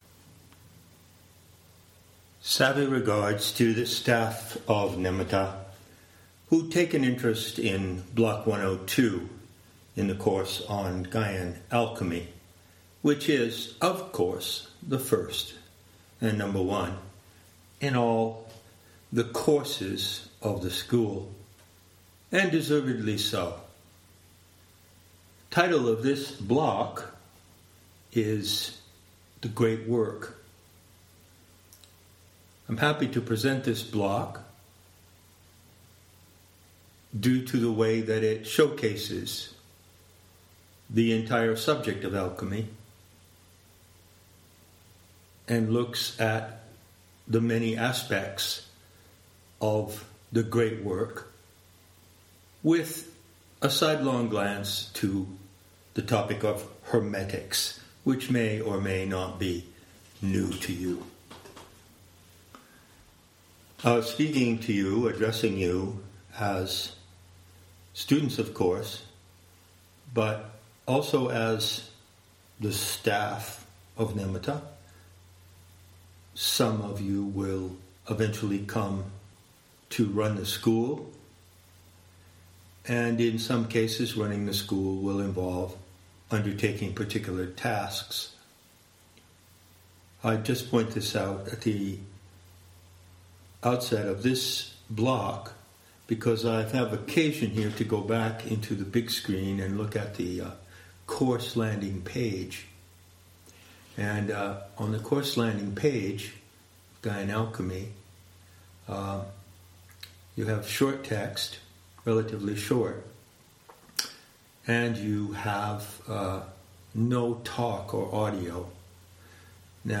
Introductory talk for Course 1 Block 102 Gaian Alchemy: